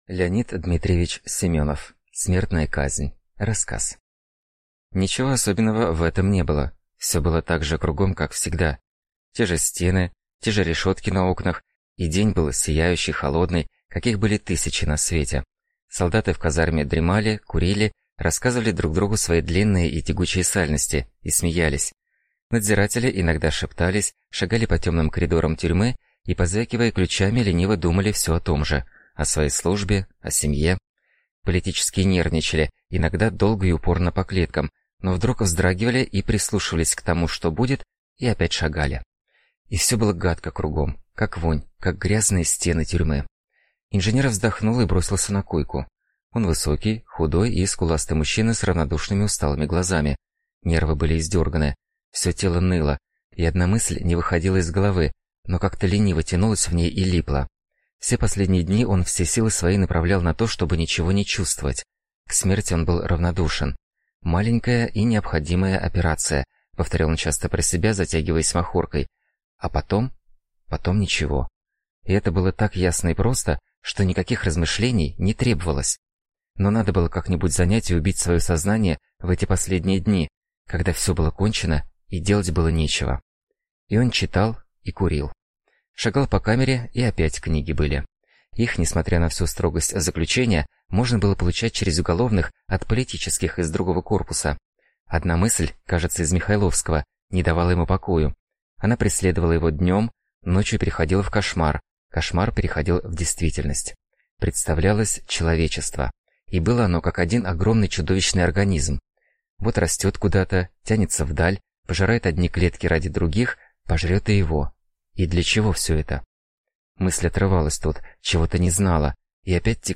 Аудиокнига Смертная казнь | Библиотека аудиокниг